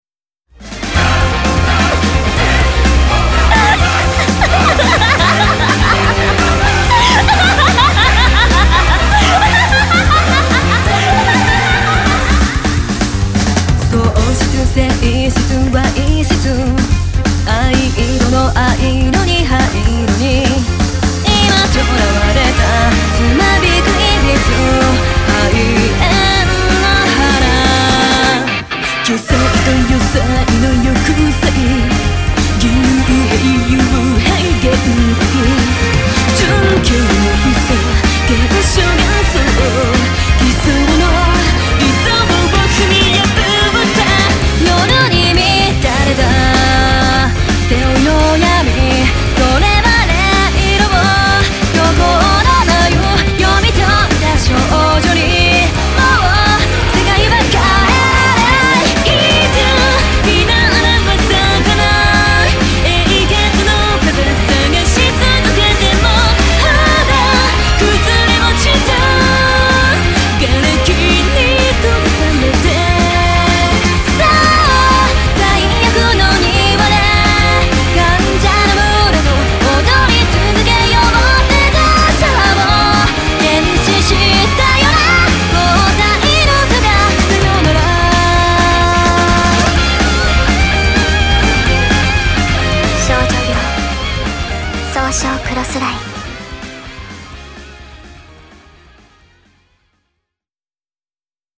Vocal
Voices
Guitar
Bass
Drums
Chorus
Strings